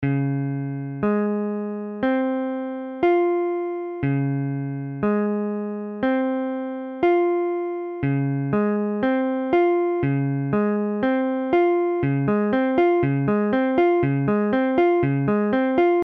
Db7M : accord de R� b�mol septi�me majeure Mesure : 4/4
Tempo : 1/4=60
A la guitare, on r�alise souvent les accords de quatre notes en pla�ant la tierce � l'octave.